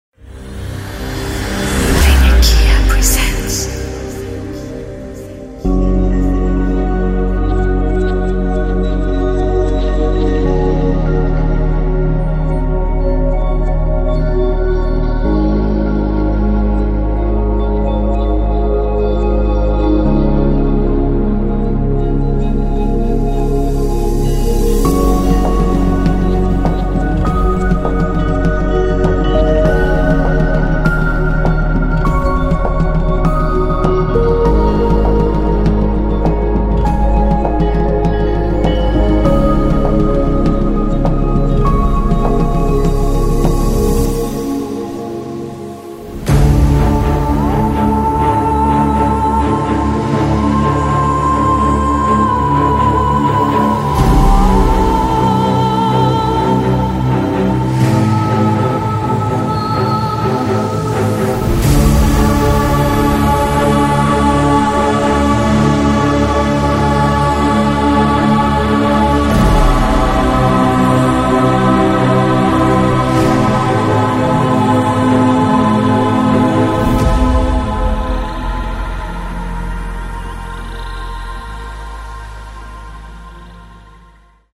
想要制作史诗般的电影配乐或放松放松吗？
从多汁的打击垫、流畅的钢琴旋律到不断变化的纹理，应有尽有。
• 类型：MIDI、音效采样包
• 采样：32 bit 44.1 kHz stereo